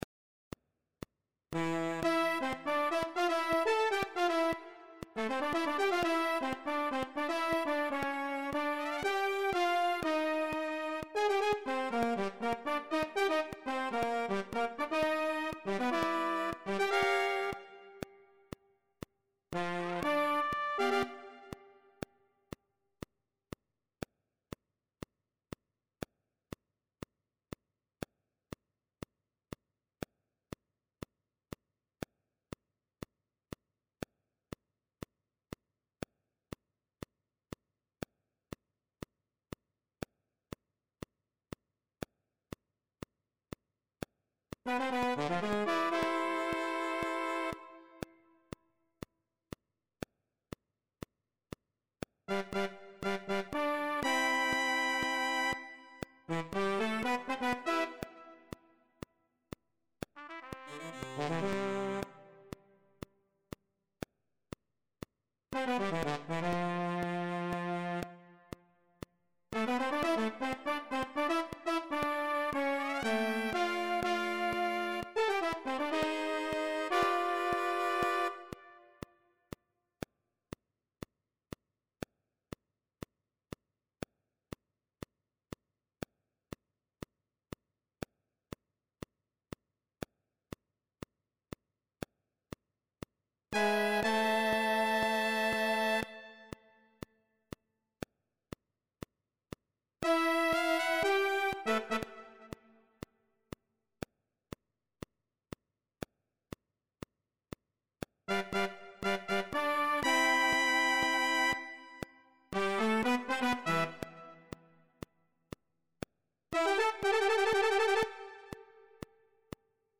TOM ORIGINAL.
1. Sax Tenor;
2. Sax Alto;
3. Trompete; e,
4. Trombone.